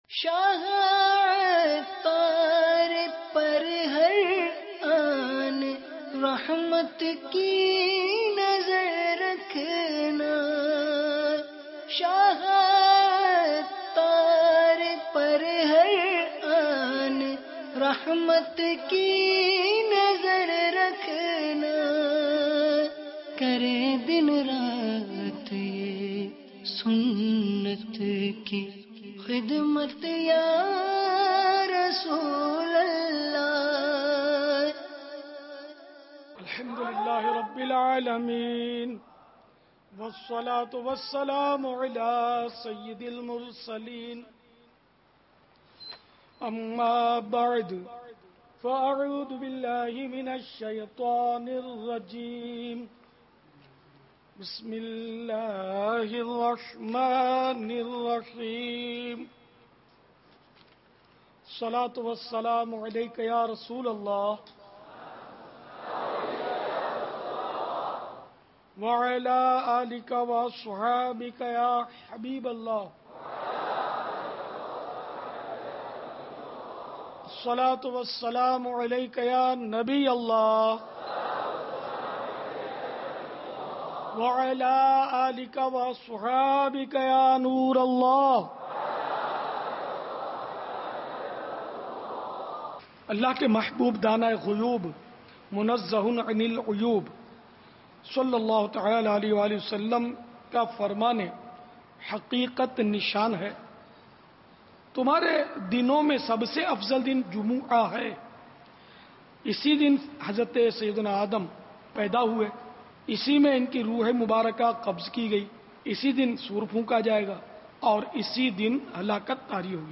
عالمی مدنی مرکز فیضانِ مدینہ میں امیرِ اہلسنت دامت برکاتہم العالیہ نے 6 رجب المرجب کو ہونے والےسنتوں بھرے بیان میں درود پاک کی فضیلت اور خواجہ غریب نواز رحمۃ اللہ علیہ کے بارے میں مدنی پھولوں سے نوازا